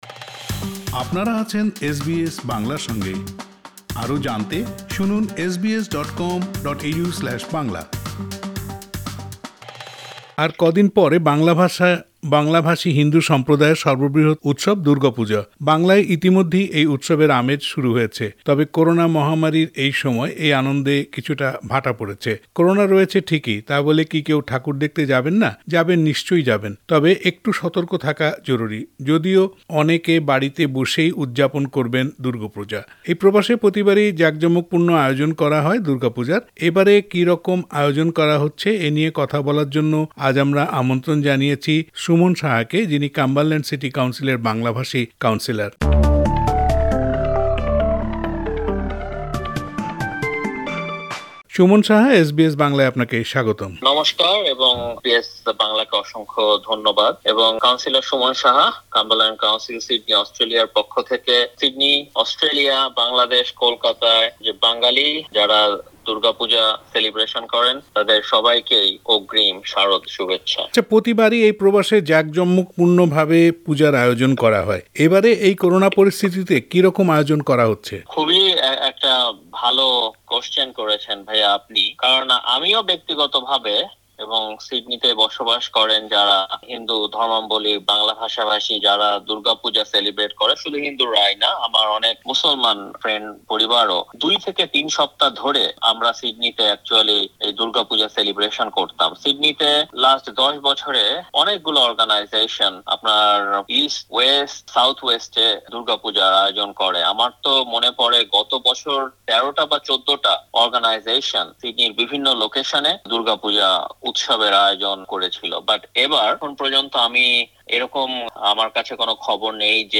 যদিও অনেকে বাড়ি বসেই উদযাপন করবেন দুর্গা পুজো।এই প্রবাসে প্রতিবারই জাকজমক পূর্ণ আয়োজন করা হয় দূর্গা পূজার।এবারের কি রকম আয়োজন হচ্ছে এ নিয়ে এস বি এস বাংলার সাথে কথা বলেছেন কাম্বারল্যান্ড সিটি কাউন্সিলের বাঙালি কাউন্সিলর সুমন সাহা। সাক্ষাৎকারটি শুনতে উপরের অডিও প্লেয়ারের লিংকটিতে ক্লিক করুন।